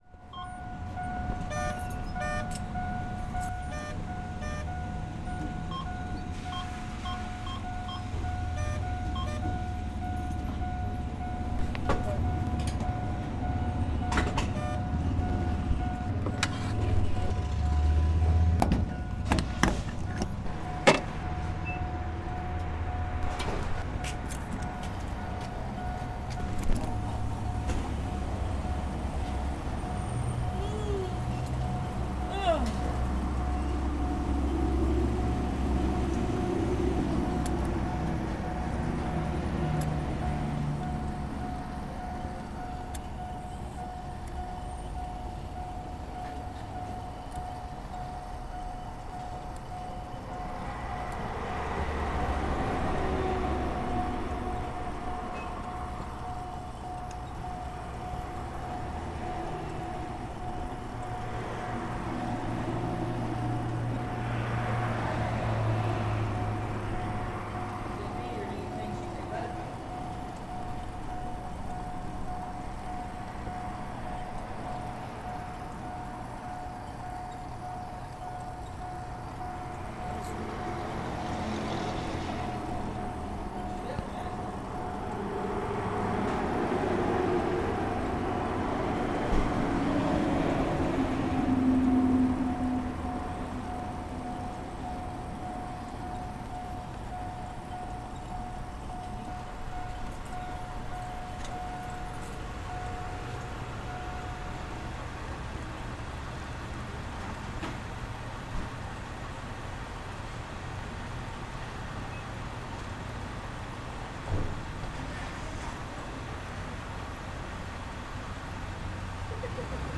Gassing up
beep buzz click ding fuel gas local people sound effect free sound royalty free Sound Effects